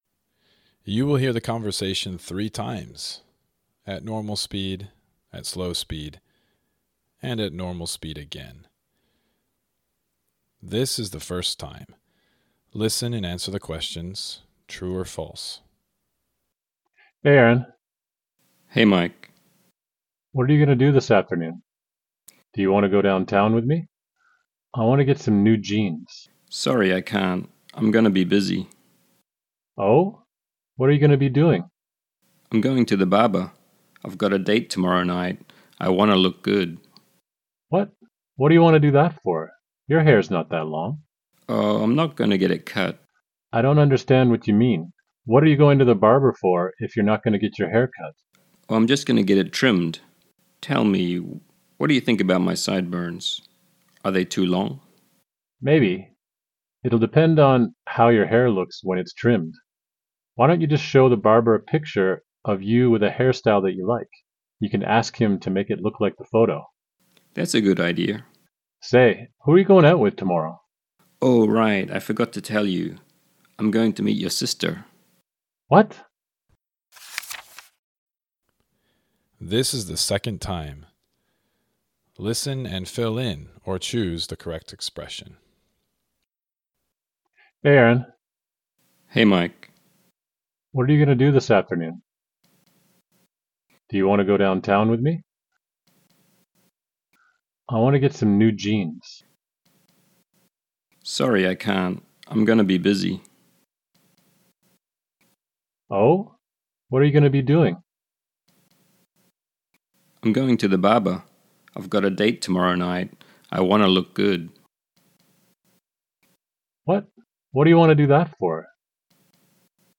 Accent: Canadian, New Zealand
Improve your understanding of English pronunciation with a conversation between two friends talking about getting a haircut.